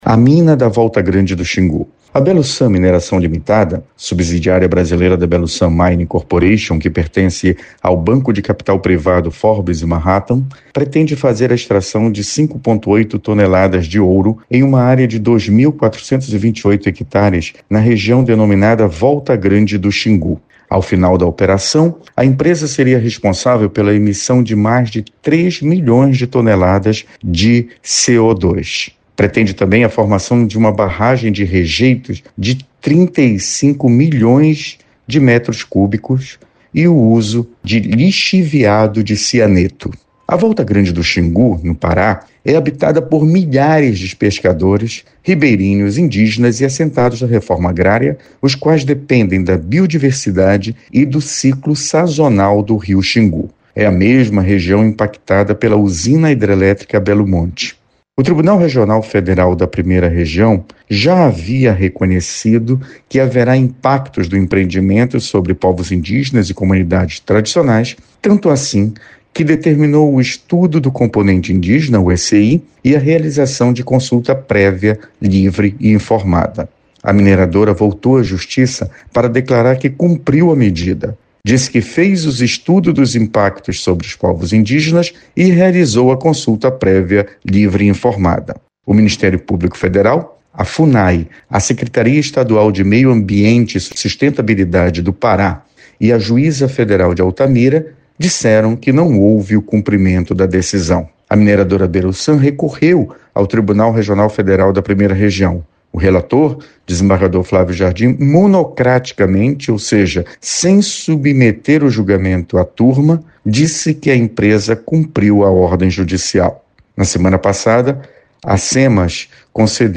Confira na íntegra o editorial com Procurador Regional da República, Felício Pontes.